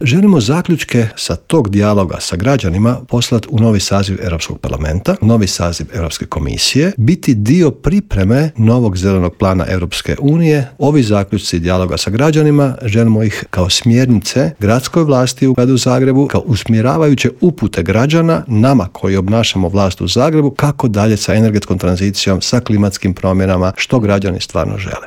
U Intervjuu Media servisa gostovao je predsjednik zagrebačke Gradske skupštine, Joško Klisović, koji je istaknuo važnost konferencije, njezine ciljeve i objasnio koliko je bitna uloga građana.